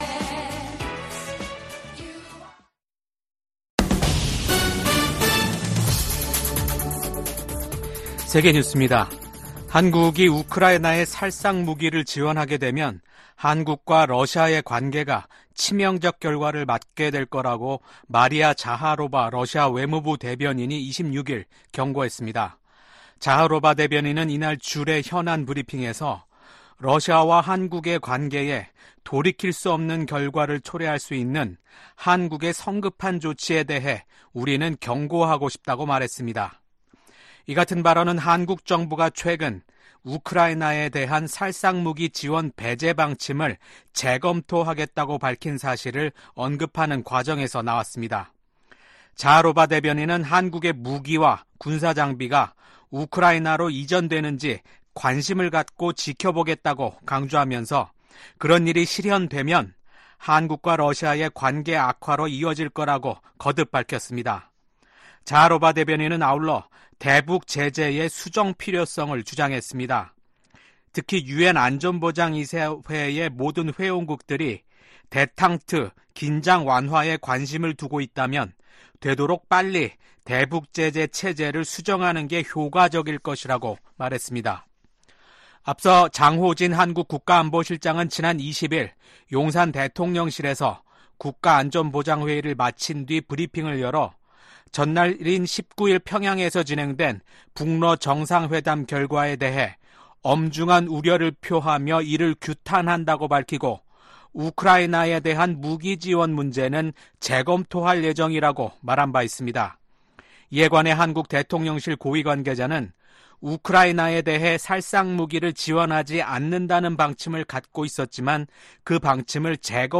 VOA 한국어 아침 뉴스 프로그램 '워싱턴 뉴스 광장' 2024년 6월 27일 방송입니다. 북한이 동해상으로 극초음속 미사일로 추정되는 발사체를 쏘고 이틀째 한국을 향해 오물 풍선을 살포했습니다. 미국 정부는 북한의 탄도미사일 발사가 다수의 유엔 안보리 결의 위반이라며 대화에 복귀할 것을 북한에 촉구했습니다.